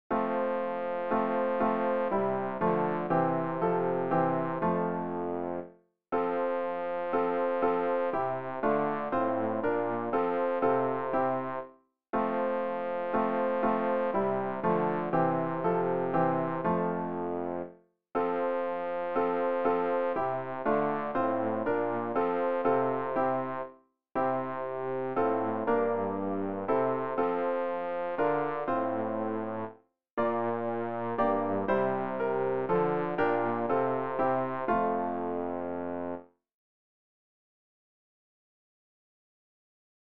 rg-856-jesus-ist-der-siegesheld-bass.mp3